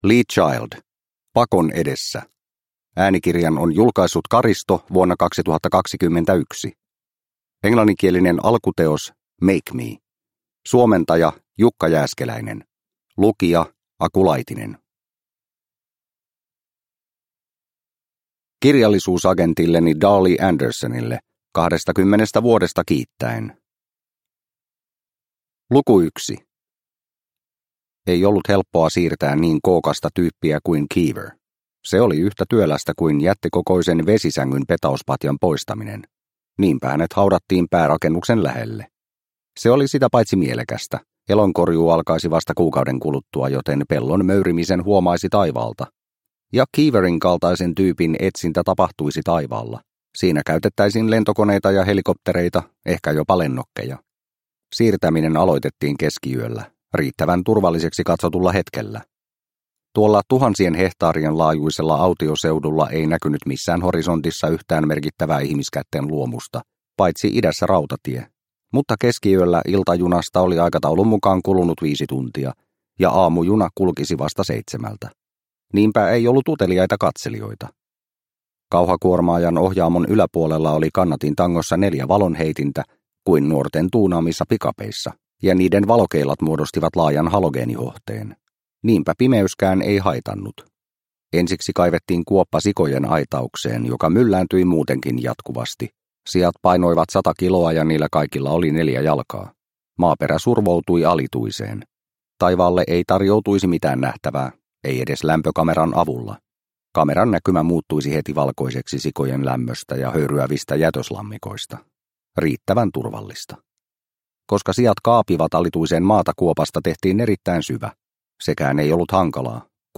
Pakon edessä – Ljudbok – Laddas ner